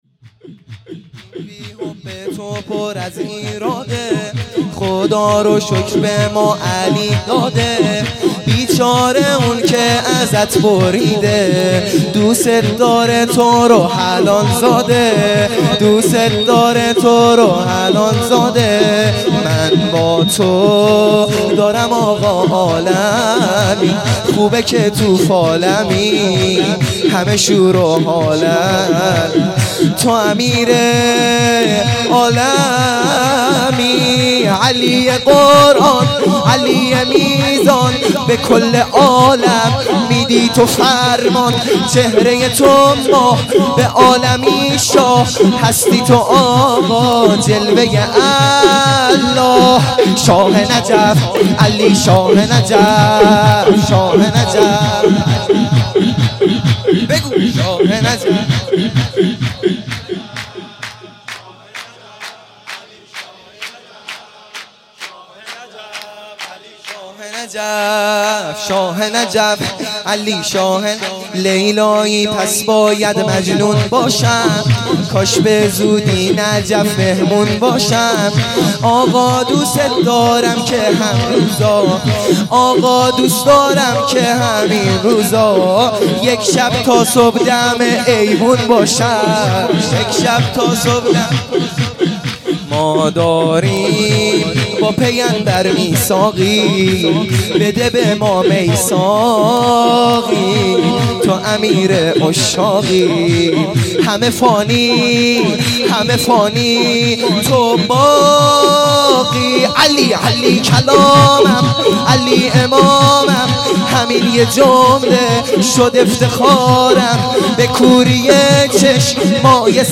سرود | دین بی حب تو پر ایراده